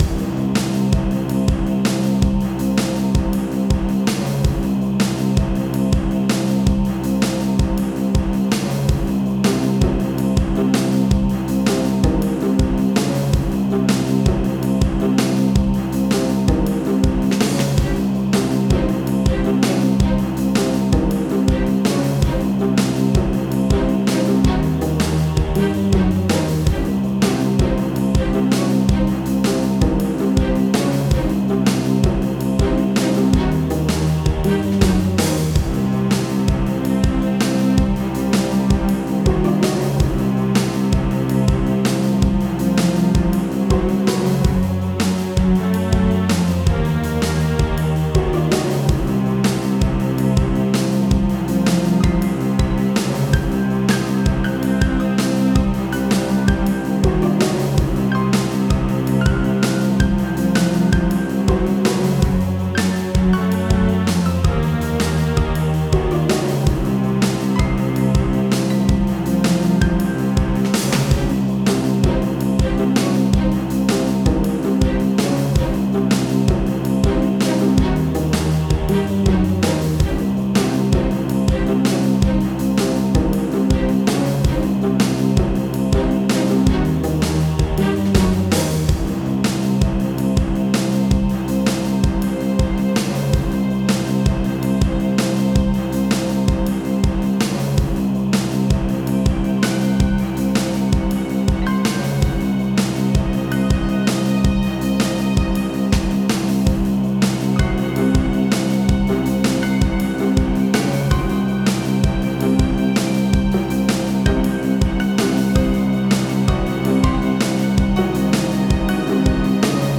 Another Dungeon track